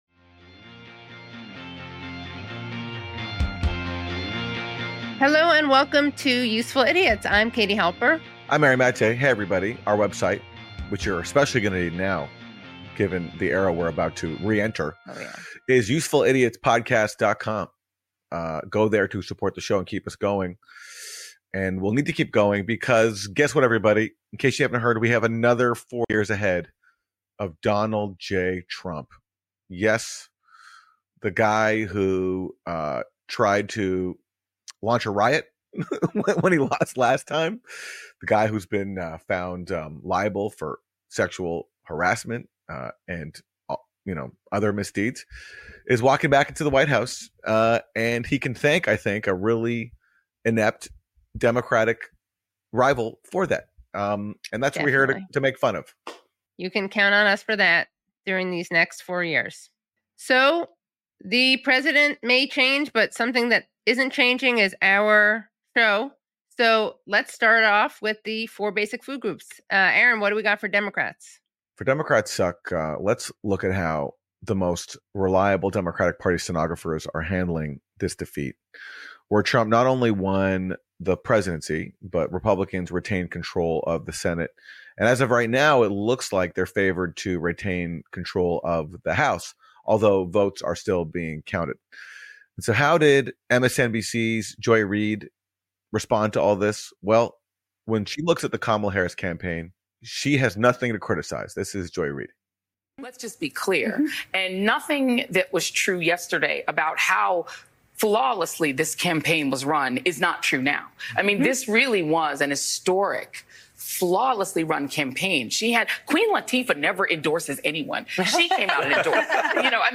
1 Leviticus 11-15, Everything Clean and Unclean, Sleep Bible Reading 57:08 Play Pause 3h ago 57:08 Play Pause Play later Play later Lists Like Liked 57:08 Send me a Text Message A relaxing reading of chapters 11 to 15 from Leviticus. This is where God tells Moses all the laws and instructions surrounding clean and unclean foods, how to deal with mold and mildew, skin diseases and other bodily things.